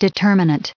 added pronounciation and merriam webster audio
213_determinant.ogg